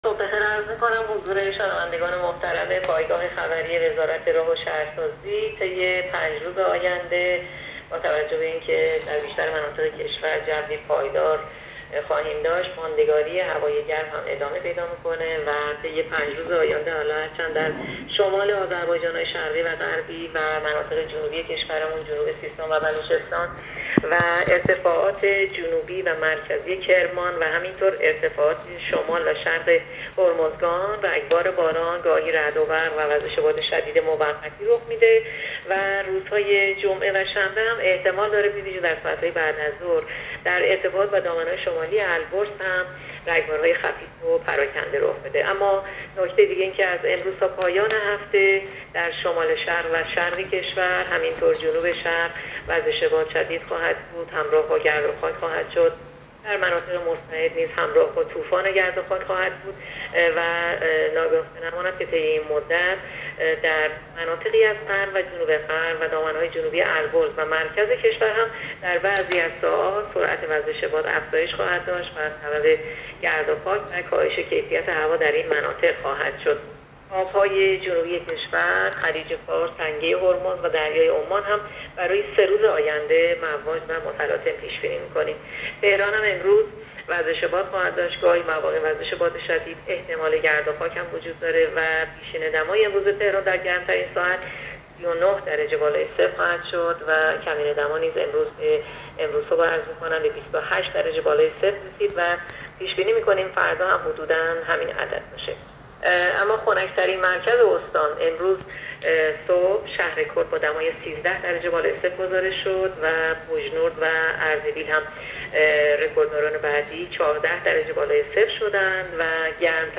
گزارش رادیو اینترنتی پایگاه‌ خبری از آخرین وضعیت آب‌وهوای ۳۱ تیر؛